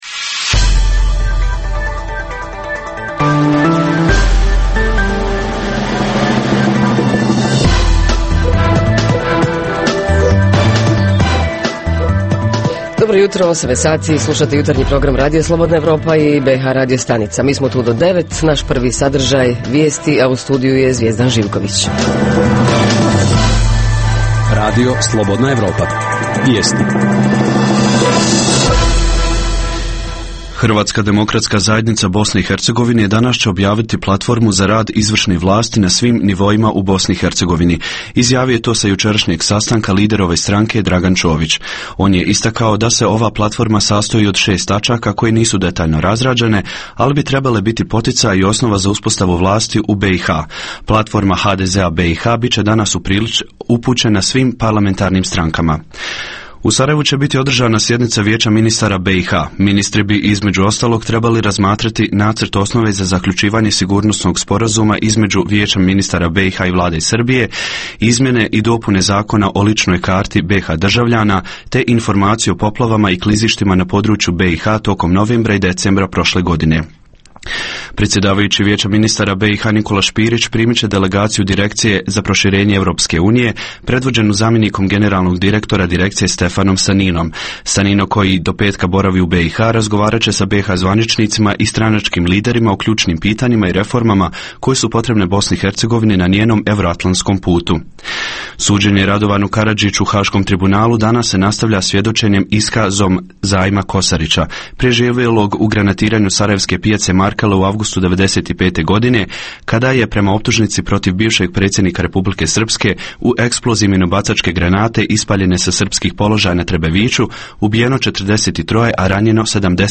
- Redovni sadržaji jutarnjeg programa za BiH su i vijesti i muzika.